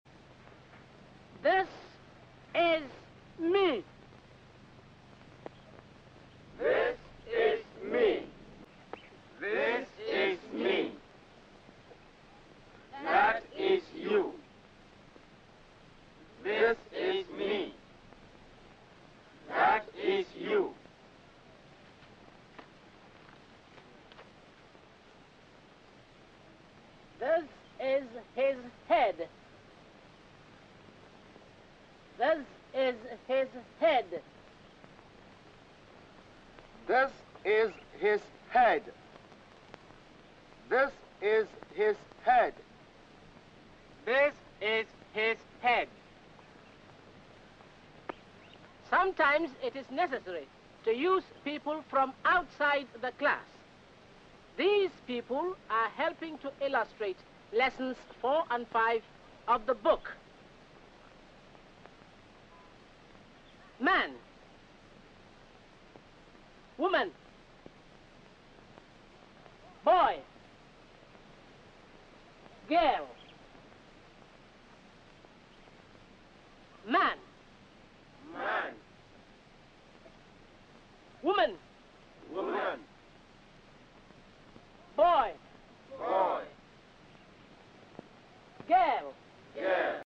As part Mp3 Sound Effect GOLD COAST 1954: As part of the mass education campaign in the Gold Coast (now Ghana) during the colonial era, here is a footage showing an African instructor demonstrating a new technique to teach English to a group of adult audiences in 1954 — under the British colonial rule.